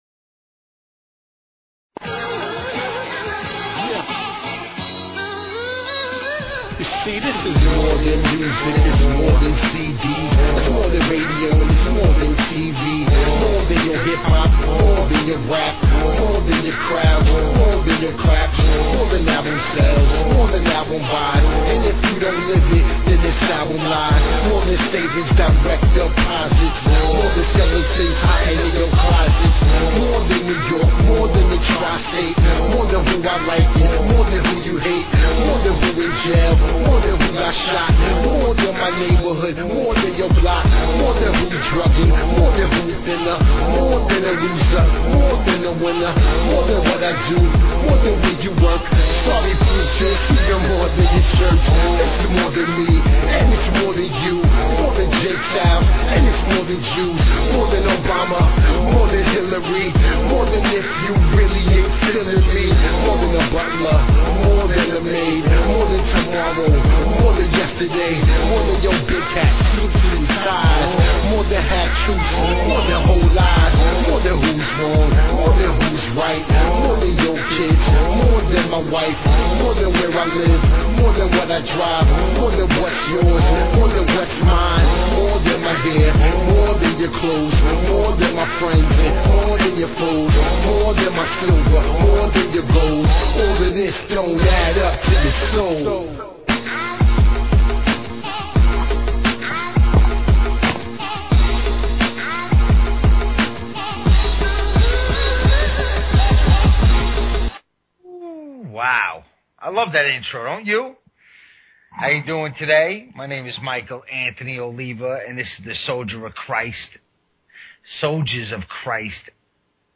Talk Show Episode, Audio Podcast, Soldiers_of_Christ and Courtesy of BBS Radio on , show guests , about , categorized as
PREACHIN THE WORD WITH CHRISTIAN HIP HOP AND SOUL!
Spreading the good word; plus hip hop and soul inspired by the Gospel!